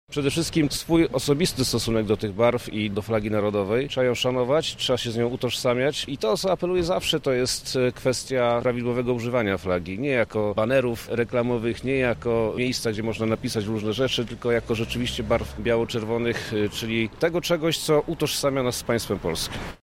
O tym, co jest najważniejsze w podejściu do polskiej flagi, mówi wojewoda lubelski, Przemysław Czarnek: